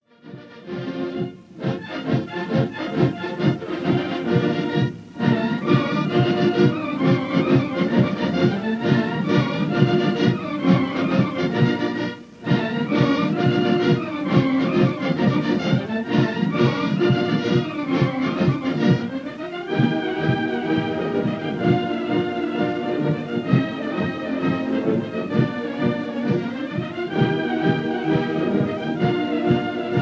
conducted by bandmaster
Recorded in Rushmoor Arena
Aldershot June 1933